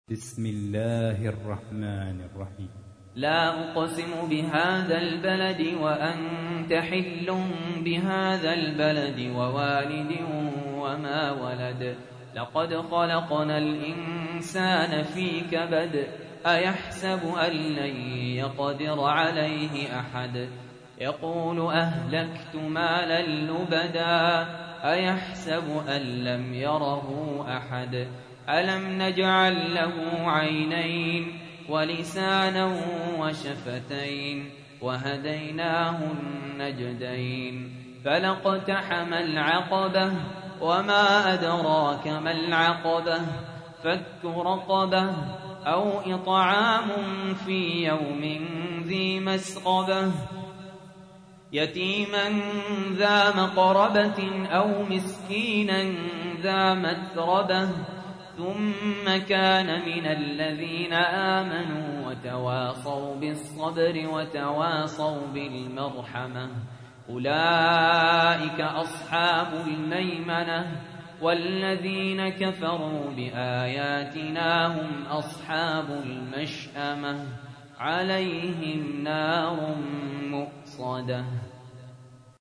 تحميل : 90. سورة البلد / القارئ سهل ياسين / القرآن الكريم / موقع يا حسين